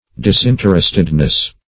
Disinterestedness \Dis*in"ter*est*ed*ness\, n.